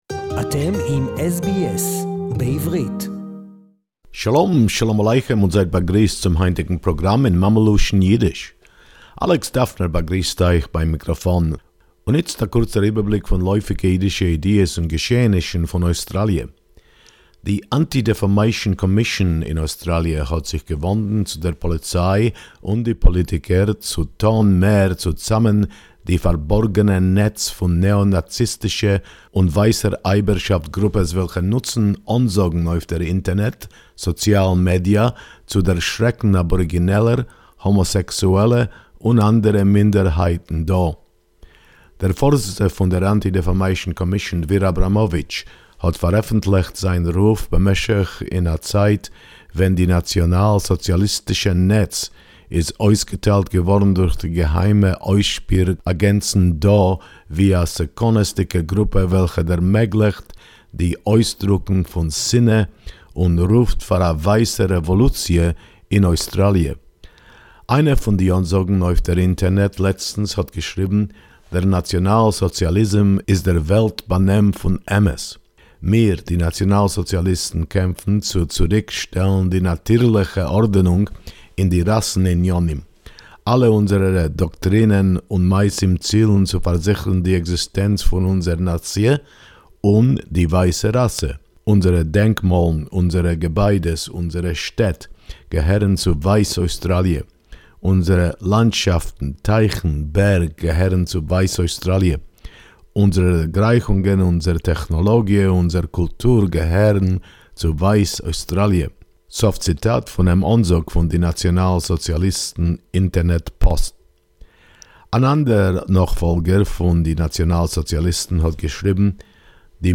Tasmanian Auction House has apologized for their trade in Nazi-era memorabilia...SBS Yiddish report 24.5.2020